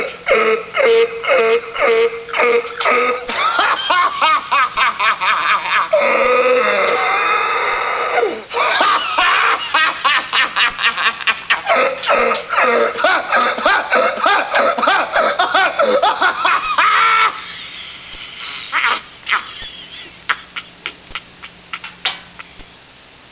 laugh1.wav